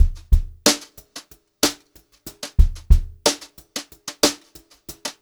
92HRBEAT2 -L.wav